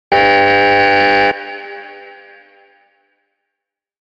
Prison Gate Buzzer Sound Effect
A distinctive buzzer tone that sounds when heavy metal prison doors unlock.
Prison-gate-buzzer-sound-effect.mp3